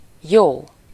Ääntäminen
IPA: /ˈjoː/